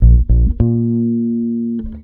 VOS BASS 3.wav